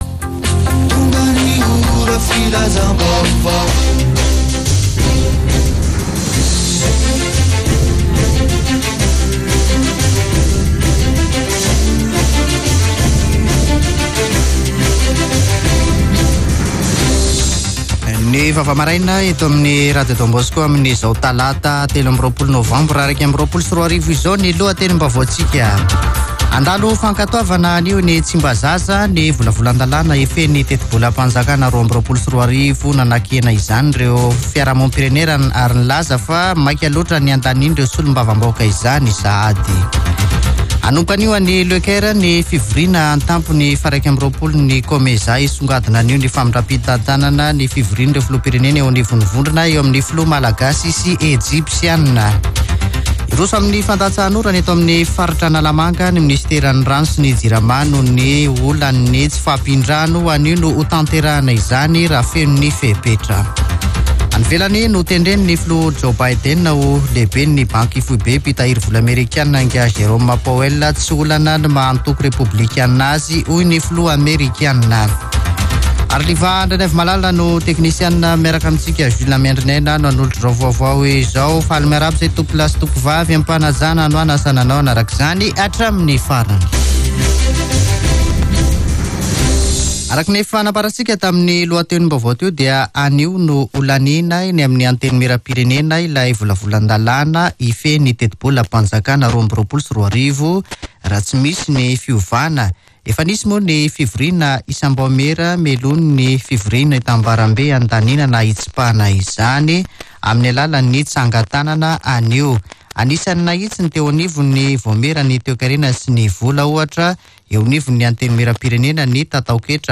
[Vaovao maraina] Talata 23 novambra 2021